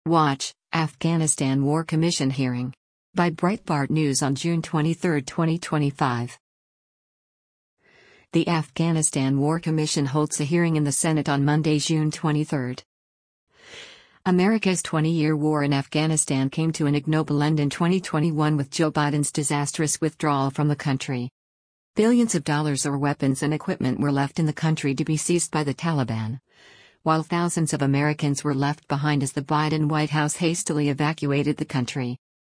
The Afghanistan War Commission holds a hearing in the Senate on Monday, June 23.